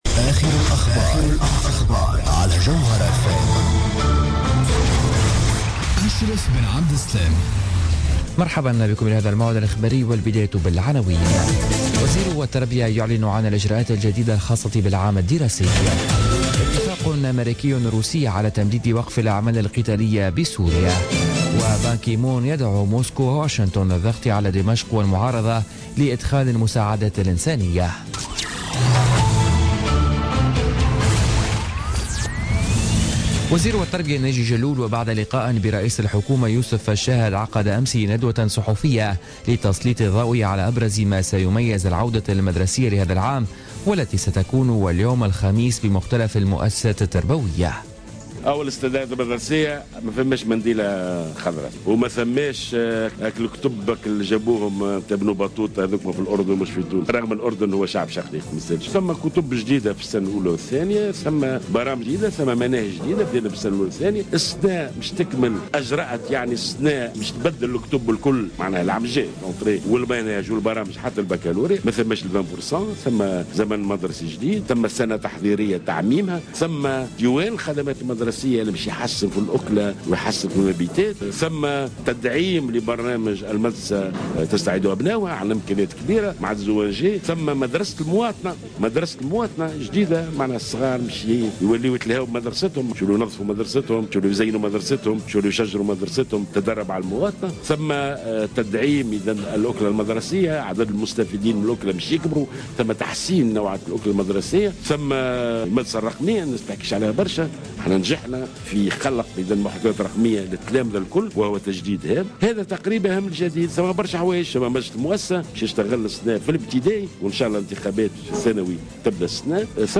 Journal Info 00h00 du jeudi 15 septembre 2016